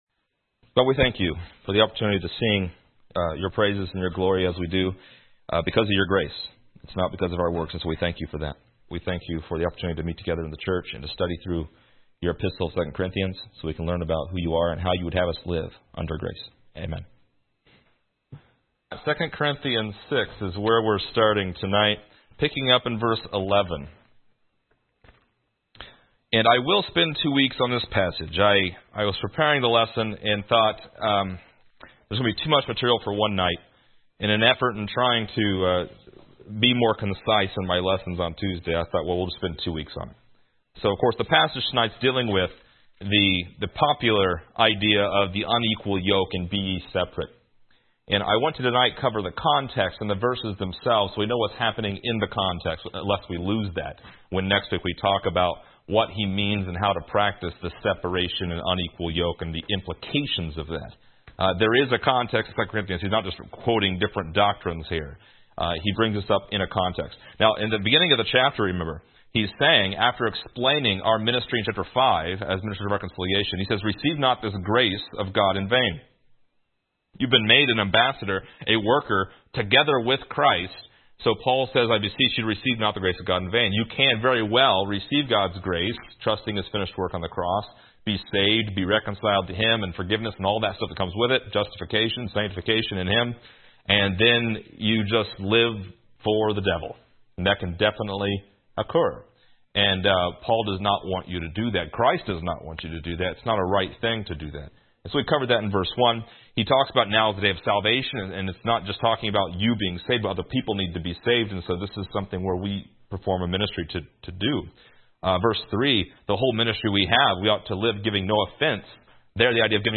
Description: This lesson is part 29 in a verse by verse study through 2 Corinthians titled: The Unequal Yoke.